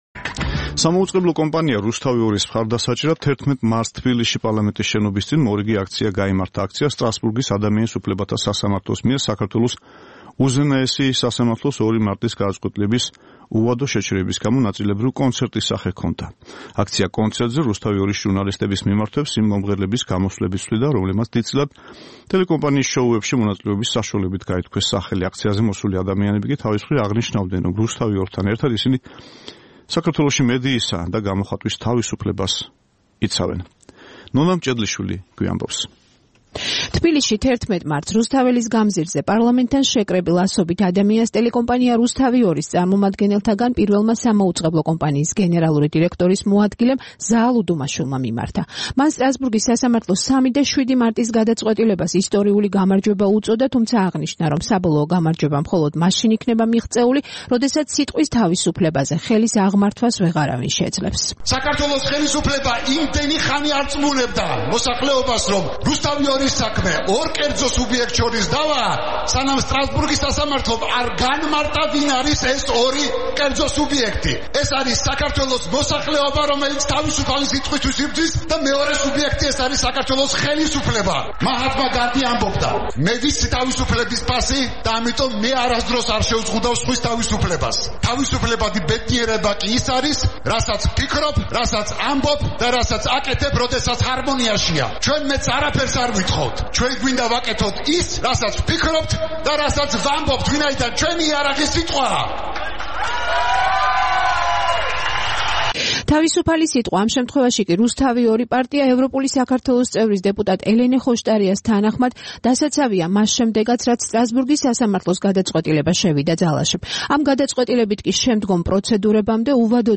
სამაუწყებლო კომპანია „რუსთავი 2-ის“ მხარდასაჭერად 11 მარტს თბილისში, პარლამენტის შენობის წინ, მორიგი აქცია გაიმართა. აქციას, სტრასბურგის ადამიანის უფლებათა სასამართლოს მიერ საქართველოს უზენაესი სასამართლოს 2 მარტის გადაწყვეტილების უვადო შეჩერების გამო, ნაწილობრივ კონცერტის სახე ჰქონდა. აქცია-კონცერტზე ,,რუსთავი 2”-ის ჟურნალისტების მიმართვებს იმ მომღერლების გამოსვლები ცვლიდა, რომლებმაც დიდწილად ტელეკომპანიის კონკურს-შოუებში მონაწილეობის საშუალებით გაითქვეს სახელი. აქციაზე მისული ადამიანები კი, თავის მხრივ, აღნიშნავდნენ, რომ ,,რუსთავი 2”-თან ერთად საქართველოში მედიისა და გამოხატვის თავისუფლებას იცავენ.